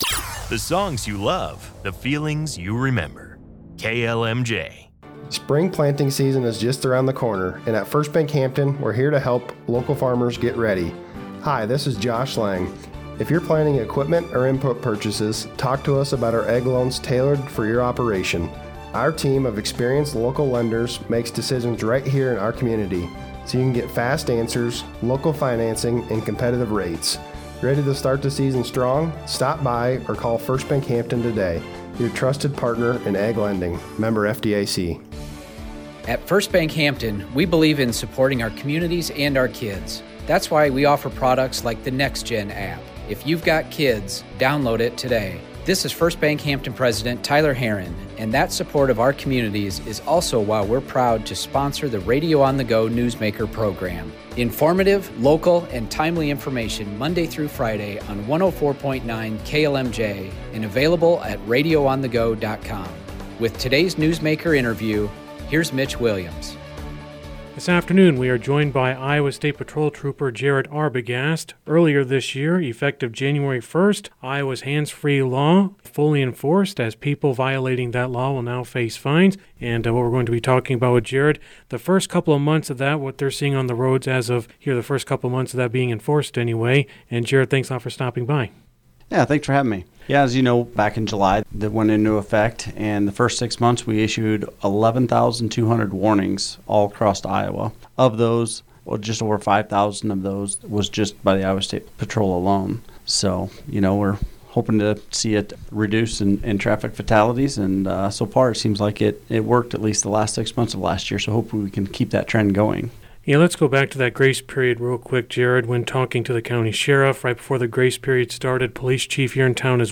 Full interview below